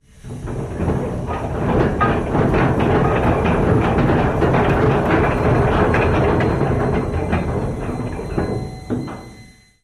Anchor is dropped from steamship. Boat, Anchor